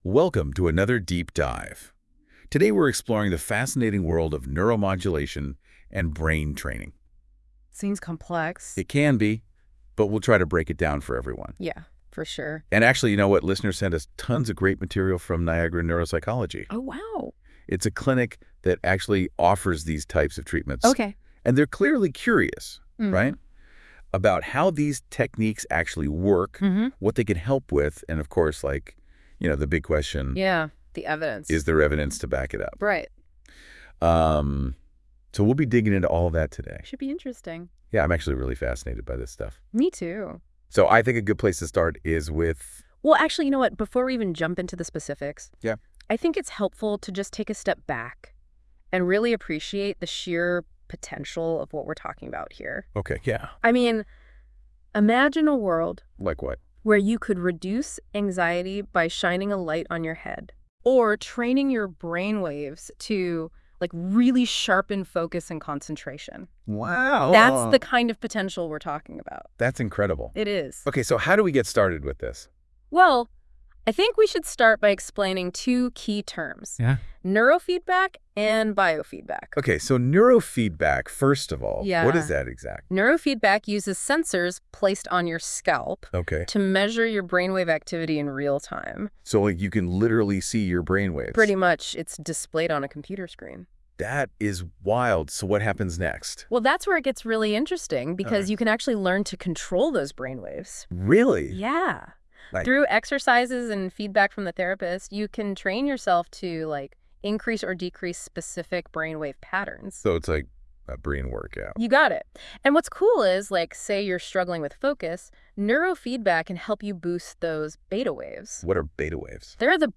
CLICK TO HEAR AN AI GENERATED DISCUSSION OF NEUROMODULATION/NEUROFEEDBACK/BIOFEEDBACK DISCUSSED ON THIS PAGE: